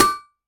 hammer.wav